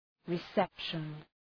{rı’sepʃən}